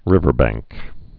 (rĭvər-băngk)